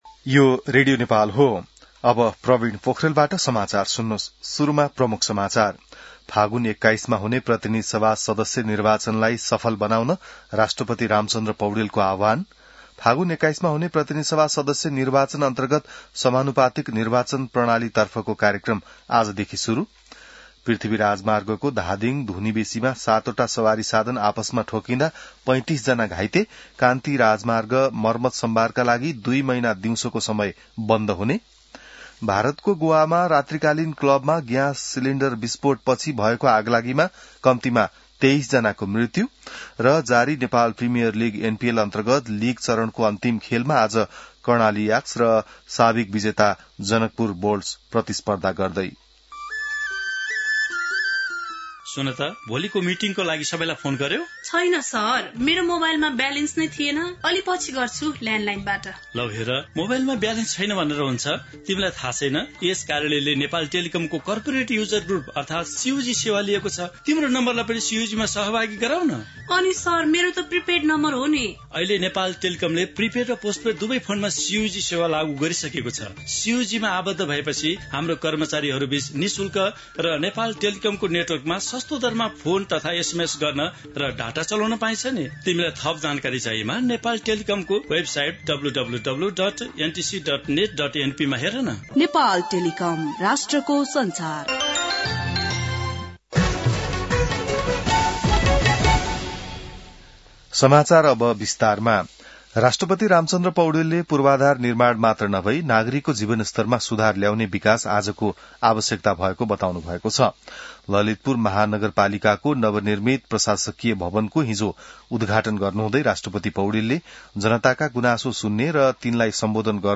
बिहान ७ बजेको नेपाली समाचार : २८ असार , २०८२